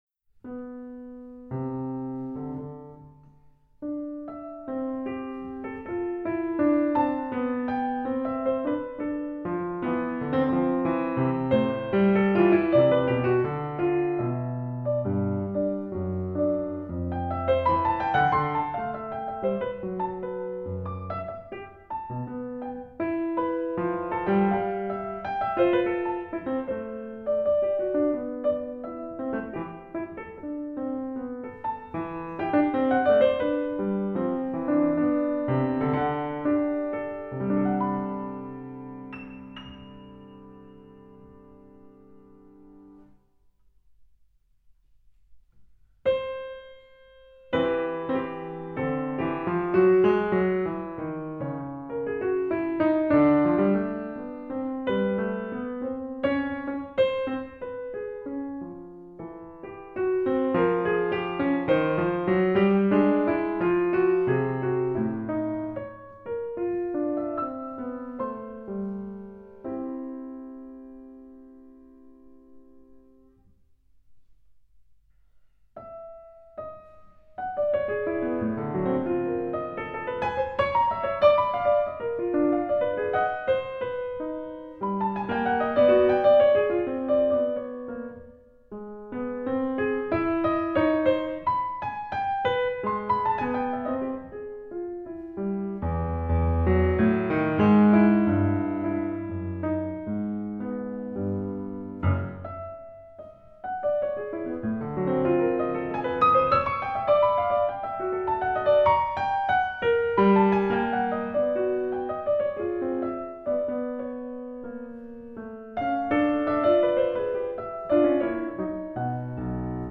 per piano
Pianowerken